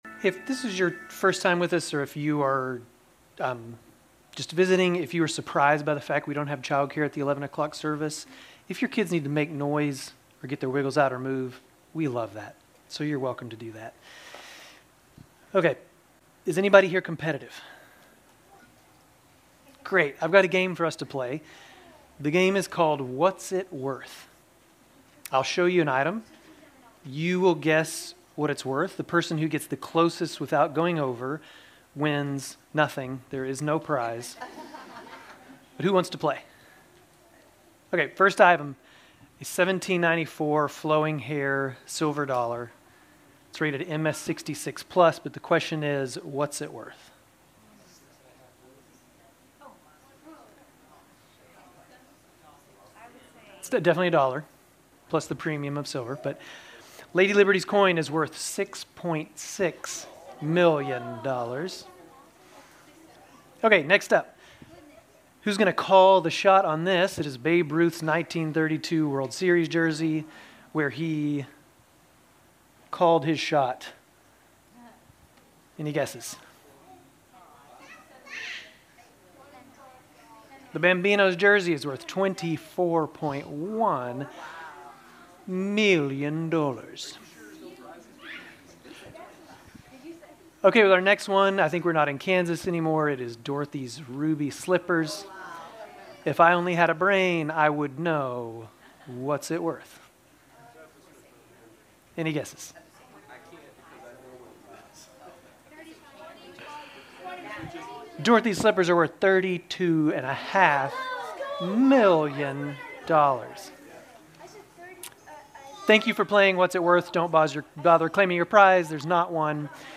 Grace Community Church Dover Campus Sermons 11_16 Dover Campus Nov 17 2025 | 00:24:53 1x 00:00 / 00:24:53 Subscribe Share RSS Feed Share Link Embed